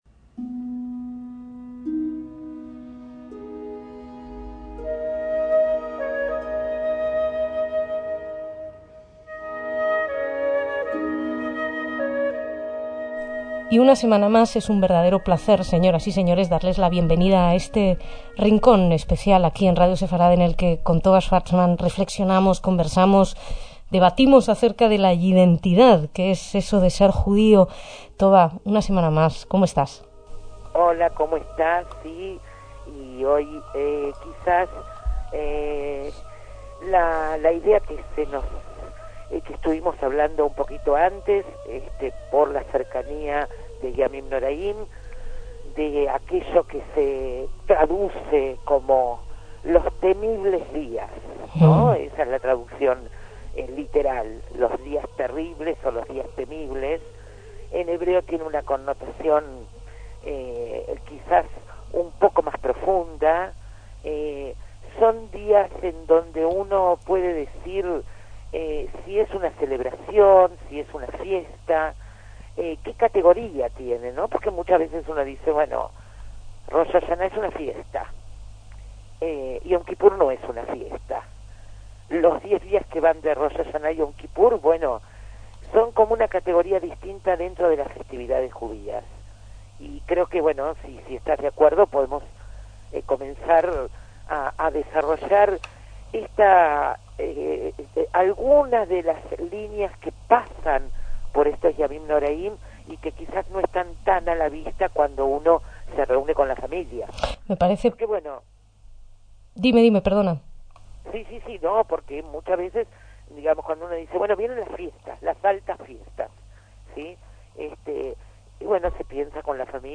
YIDENTIDAD: CONVERSACIONES SOBRE LO JUDÍO